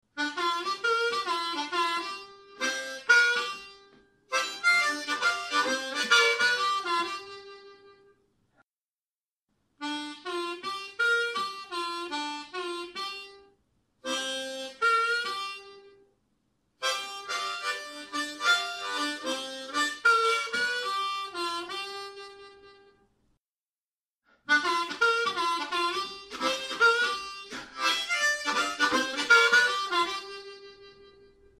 Fraseos de blues de tipo « llamada y respuesta »
Este ejercicio mejora nuestro uso de las articulaciones complejas de la técnica del tuongue blocking como slaps, pulls y octavas. Tocaremos fraseos típicos usando las notas de la escala blues.
AHCOD - Audio_Call and answer blues licks.mp3